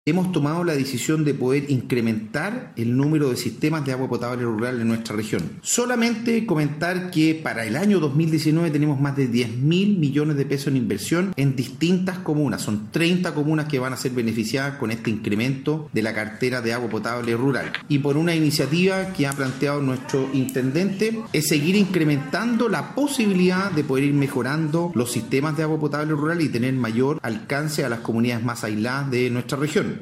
Por su parte el Seremi de Obras Públicas James Fry comentó que han tomado la decisión de incrementar el número de sistemas de Agua Potable Rural, donde ya hay 10 mil millones de pesos para las 30 comunas.